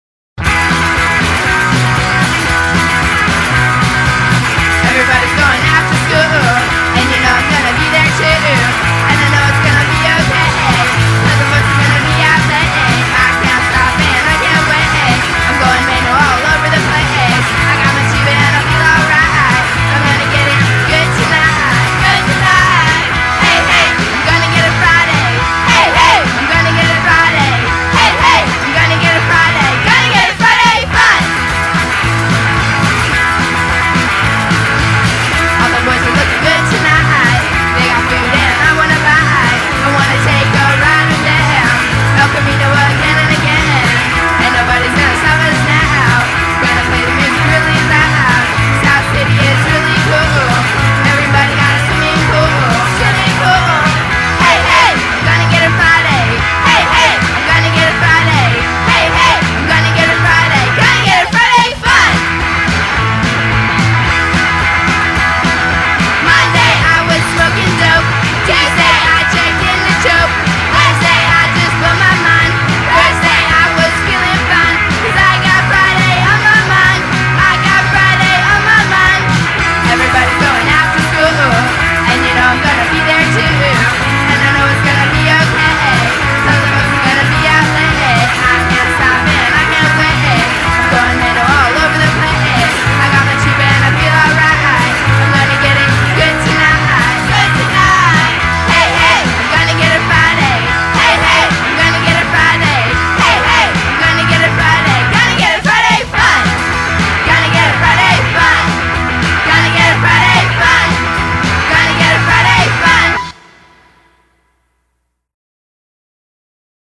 unbounded girlish glee
Punk